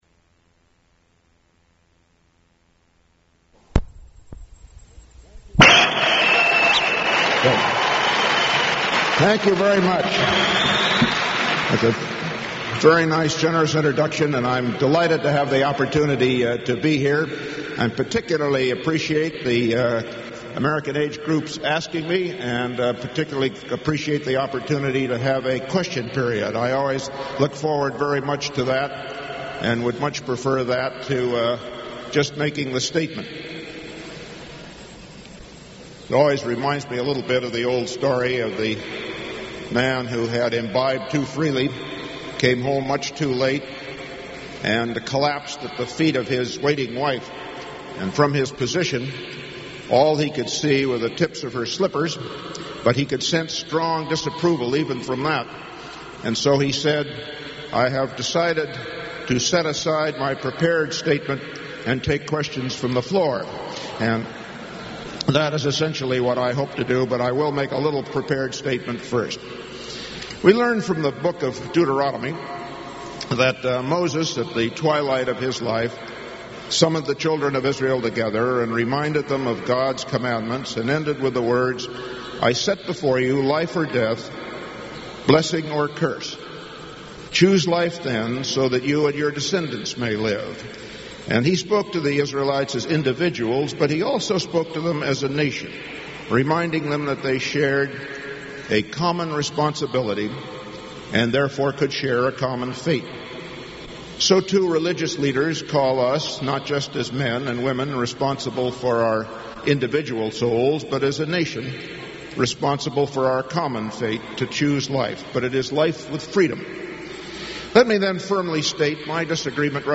U.S. Secretary of Defense Caspar Weinberger speaks at Fordham University